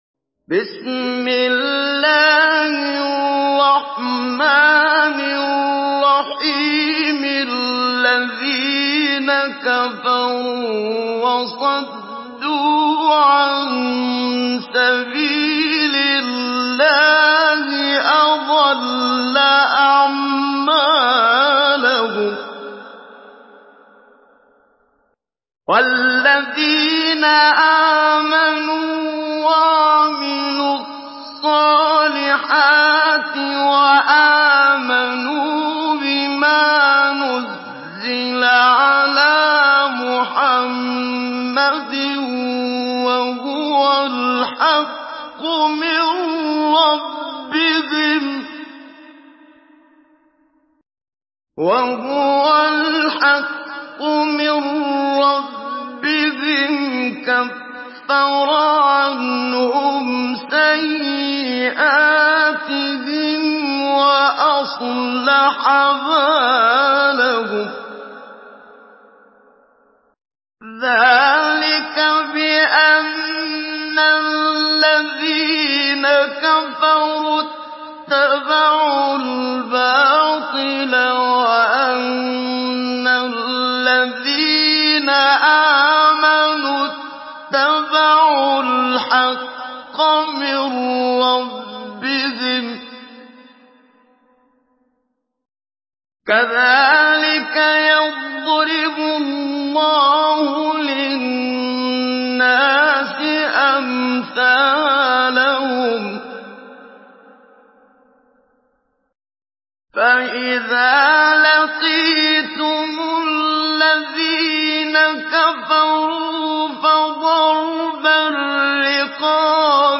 Surah Muhammad MP3 in the Voice of Muhammad Siddiq Minshawi Mujawwad in Hafs Narration
Surah Muhammad MP3 by Muhammad Siddiq Minshawi Mujawwad in Hafs An Asim narration.